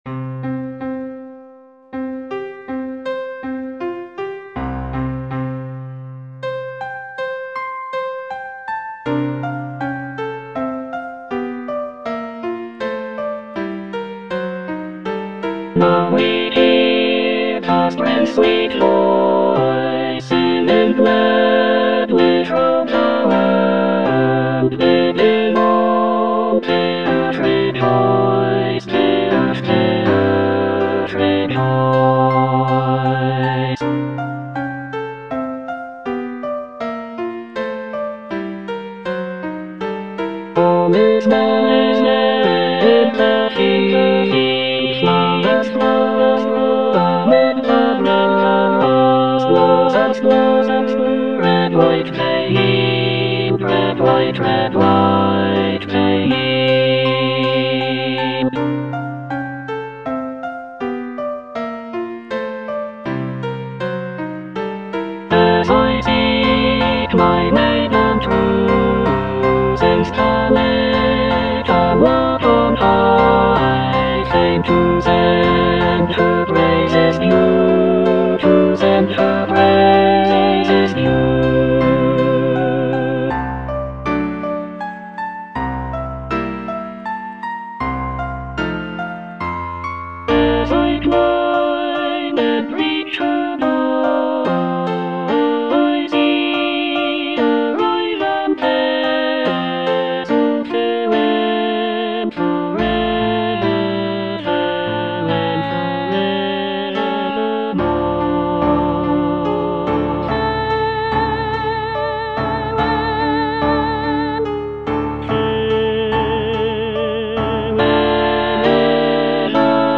E. ELGAR - FROM THE BAVARIAN HIGHLANDS False love (All voices) Ads stop: auto-stop
The piece consists of six choral songs, each inspired by Elgar's travels in the Bavarian region of Germany. The music captures the essence of the picturesque landscapes and folk traditions of the area, with lively melodies and lush harmonies.